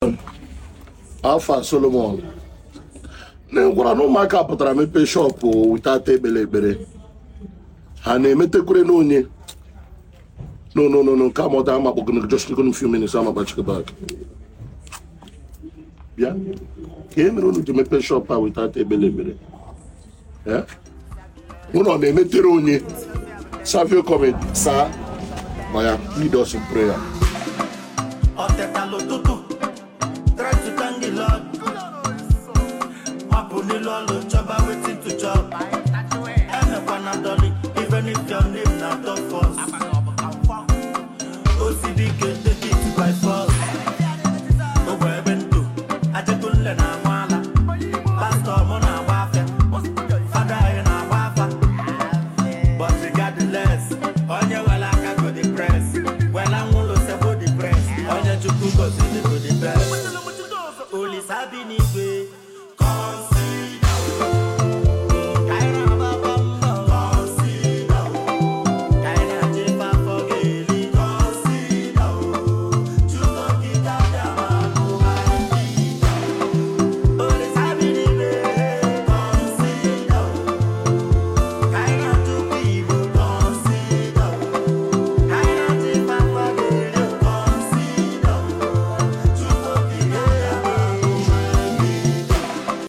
melodious, and energetic emotional song
Blending smooth vocals with dynamic instrumentals